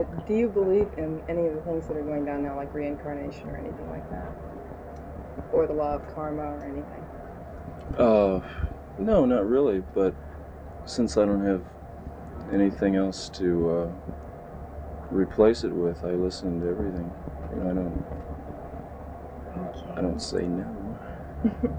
The Doors/The Lost Interview Tapes Featuring Jim Morrison - Volume Two The Circus Magazine Interview (Album)